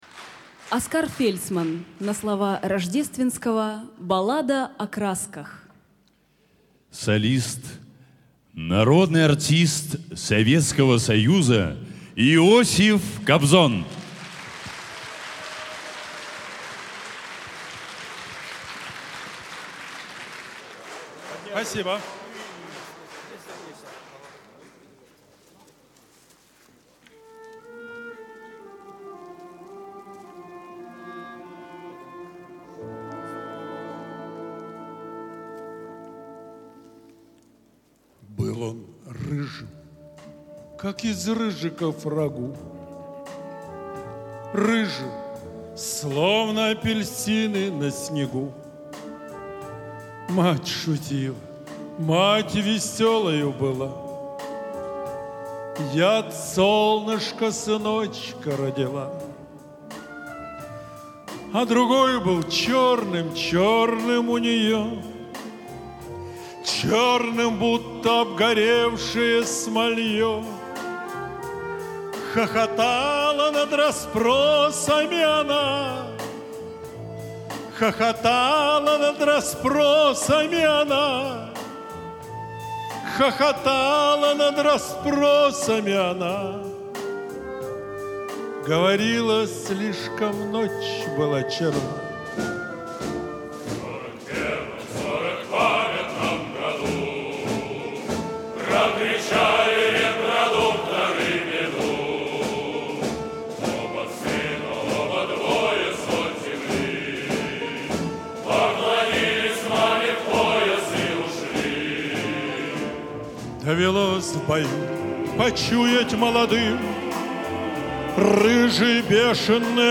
Малоизвестная концертная запись популярной песни